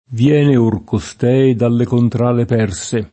perso [p$rSo] etn. — latinismo poet. per «persiano»: Viene or costei da le contrade perse [